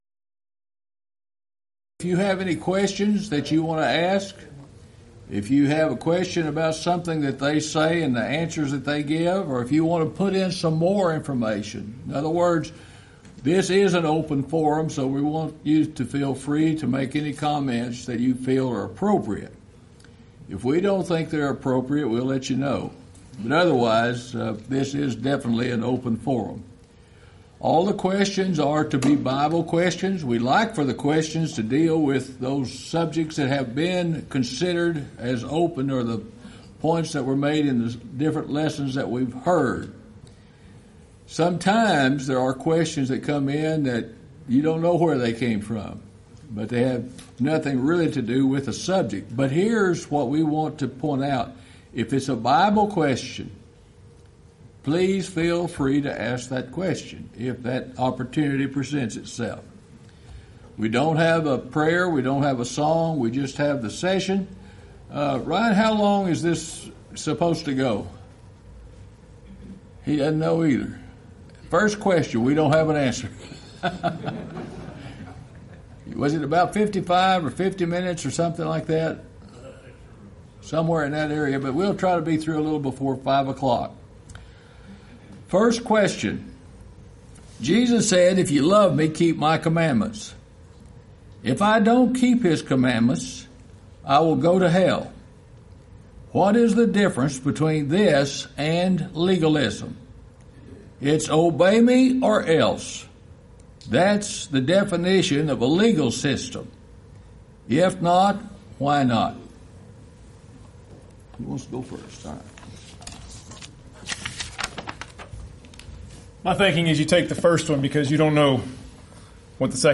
Title: Monday Open Forum Speaker(s): Various
Event: 26th Annual Lubbock Lectures Theme/Title: God is Love